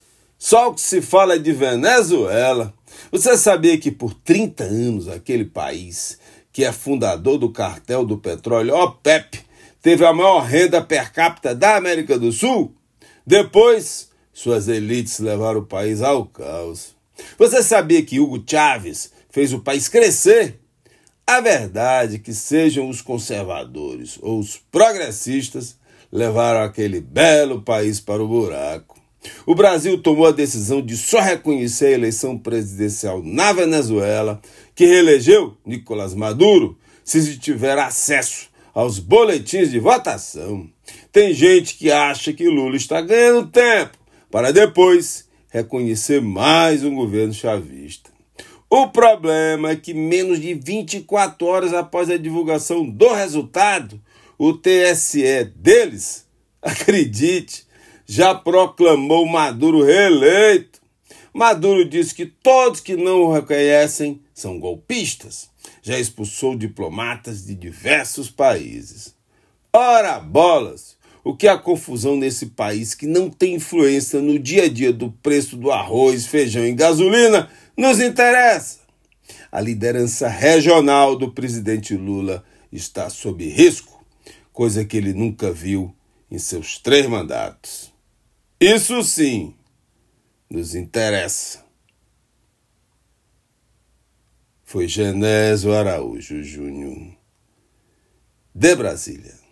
Comentário desta terça-feira
direto de Brasília.